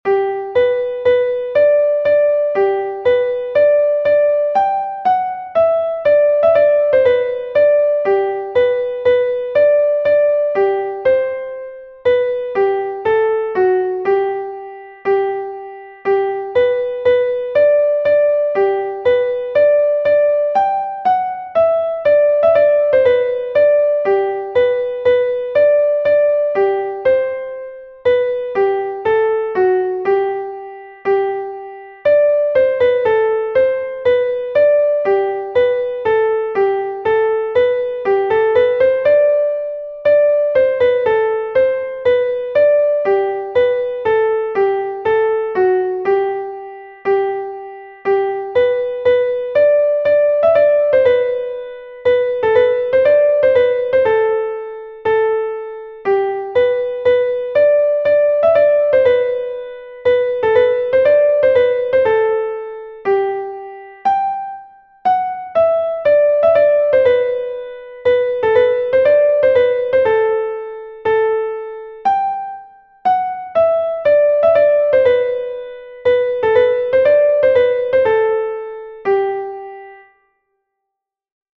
Gavotte